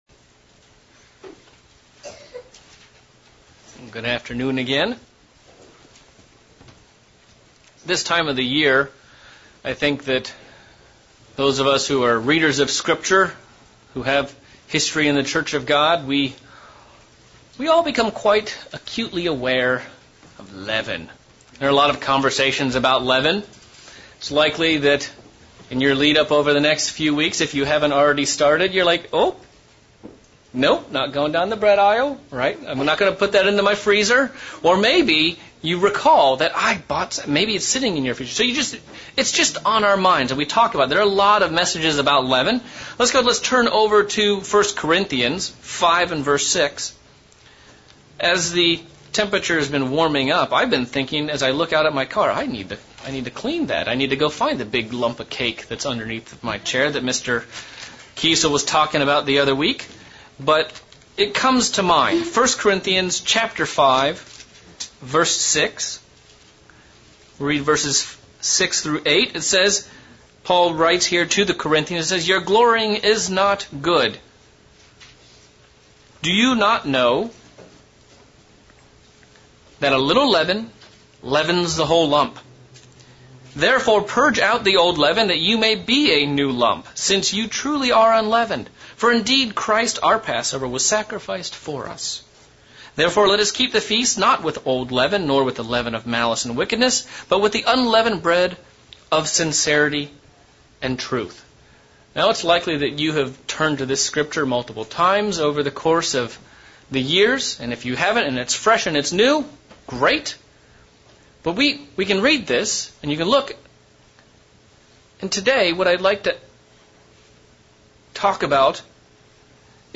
Part 1 of 2 of a sermon series on Leavening.